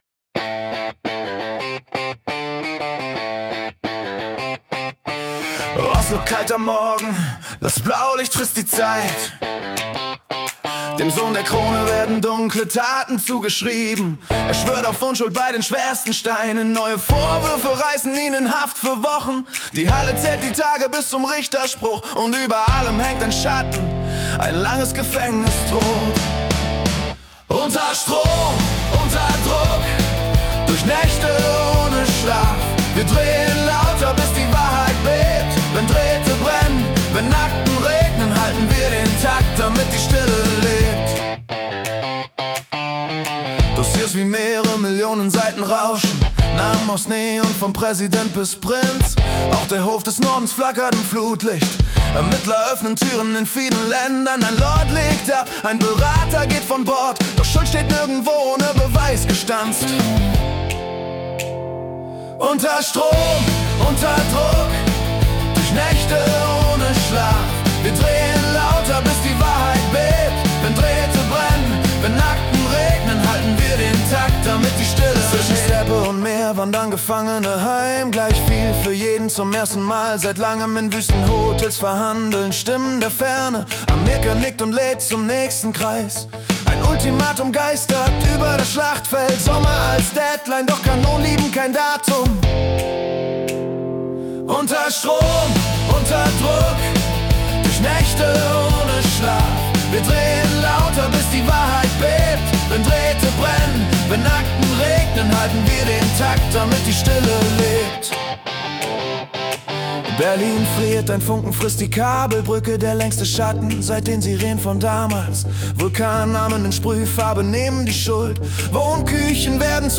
Februar 2026 als Rock-Song interpretiert.
Jede Folge verwandelt die letzten 24 Stunden weltweiter Ereignisse in eine mitreißende Rock-Hymne. Erlebe die Geschichten der Welt mit fetzigen Riffs und kraftvollen Texten, die Journalismus...